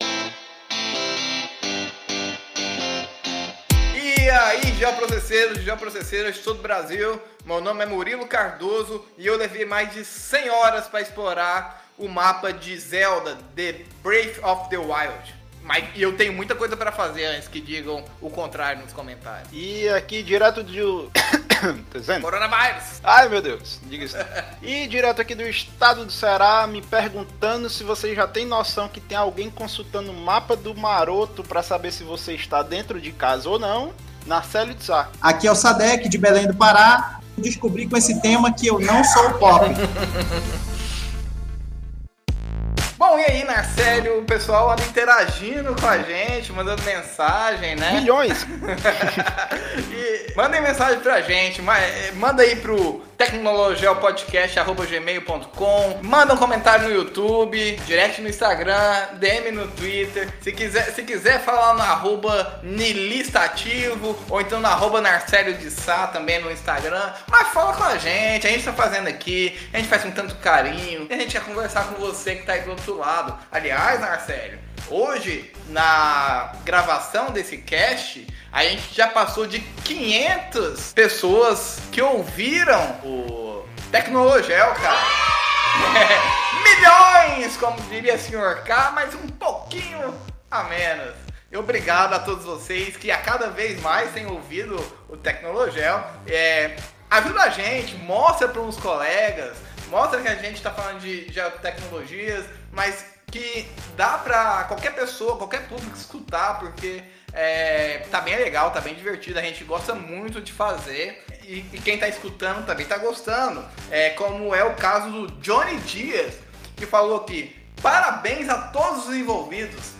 Hoje teremos a estreia do bloco de Leitura de e-mails onde iremos ler todos os recadinhos que nossos ouvintes deixaram nos últimos episódios.